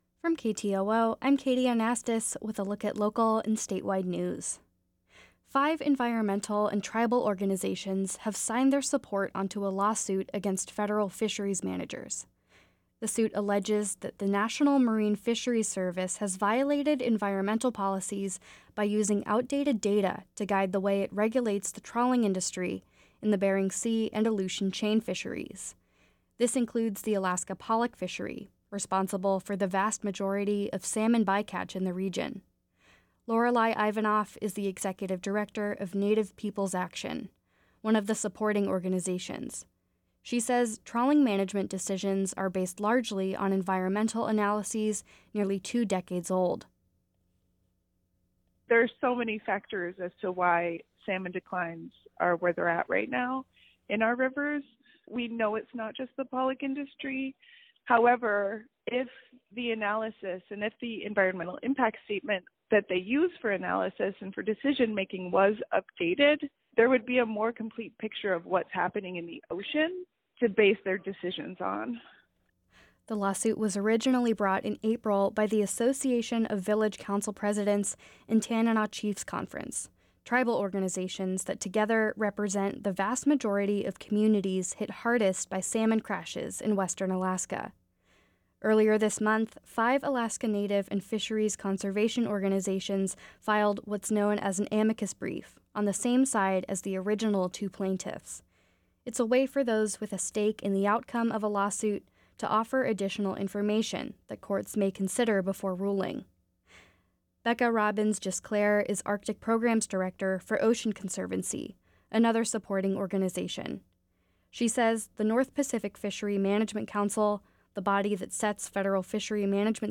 Informationcast, Friday December. 8 2023